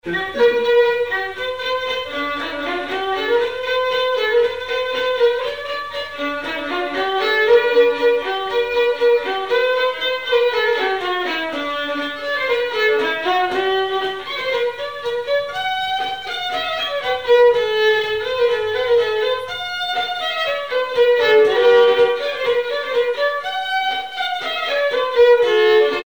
Mémoires et Patrimoines vivants - RaddO est une base de données d'archives iconographiques et sonores.
Polka
danse : polka
circonstance : bal, dancerie
Pièce musicale inédite